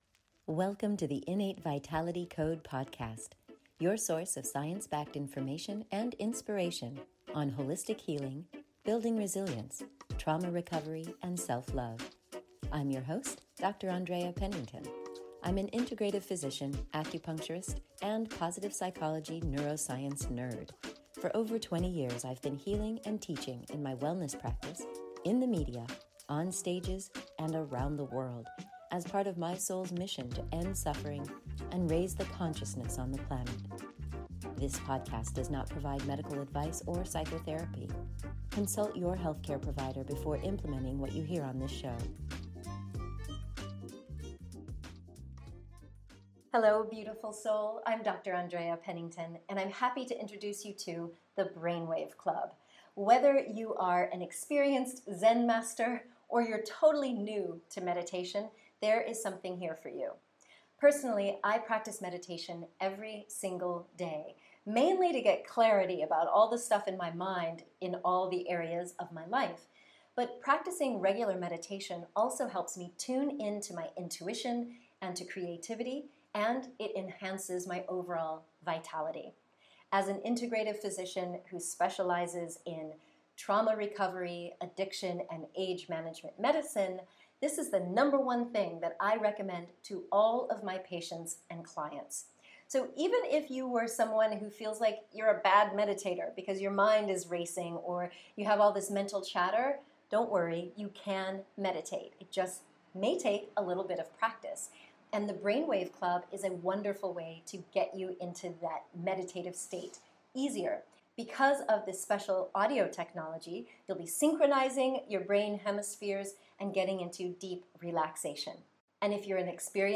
The Brainwave Club is a way to access the benefits of meditation without a rigorous meditation practice. You literally press “play” and let the embedded brainwave-guidance beats soothe your mind and body into a deep meditative state.